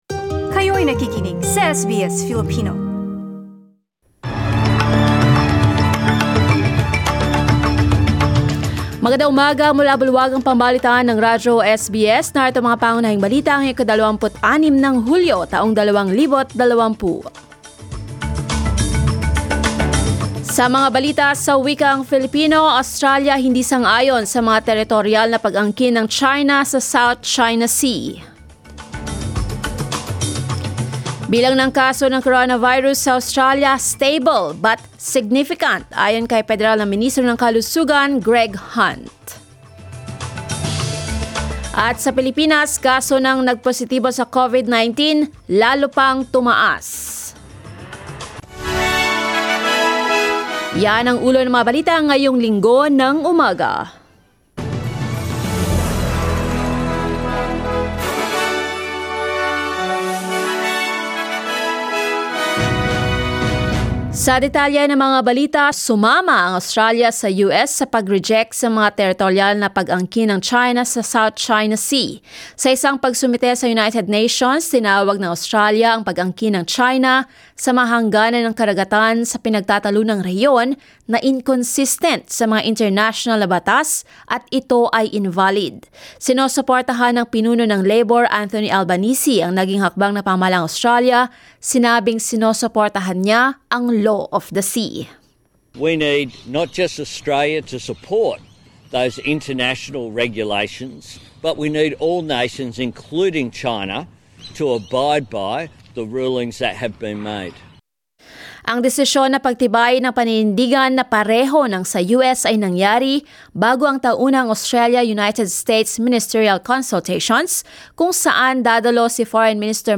SBS News in Filipino, Sunday 26 July